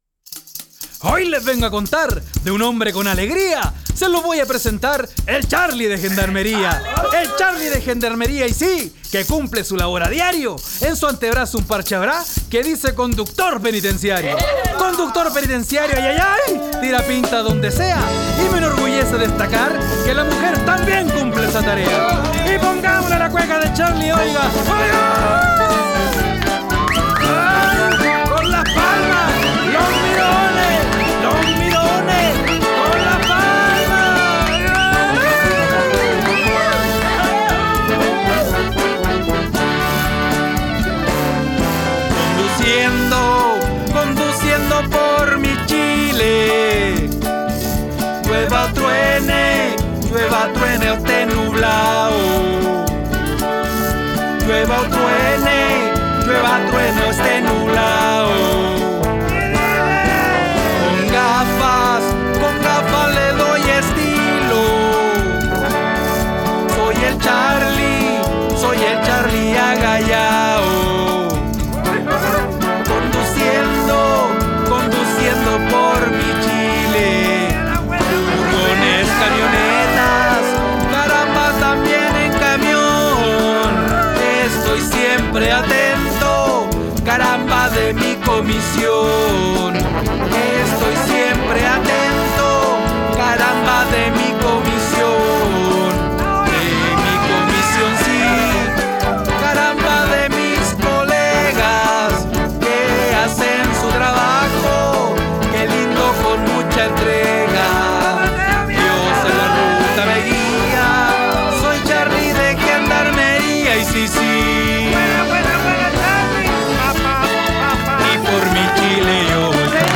Concurso folclÓrico 2023